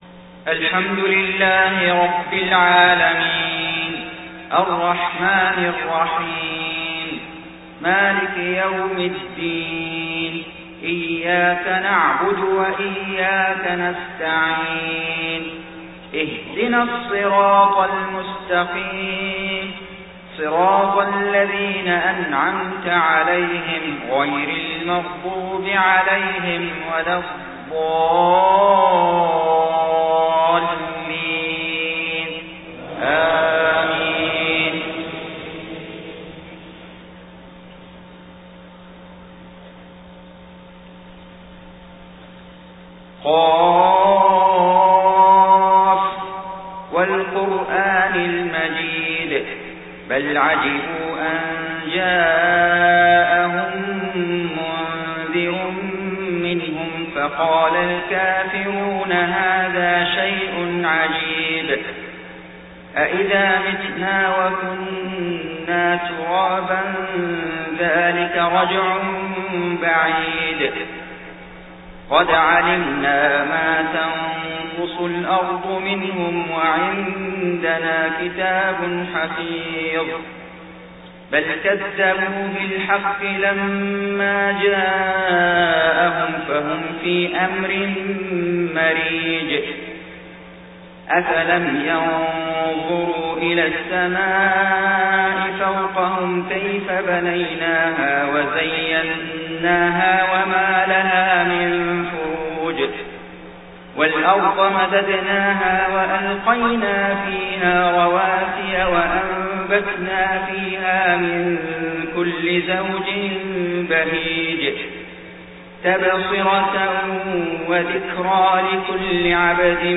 صلاة الفجر 20 صفر 1431هـ سورة ق كاملة > 1431 🕋 > الفروض - تلاوات الحرمين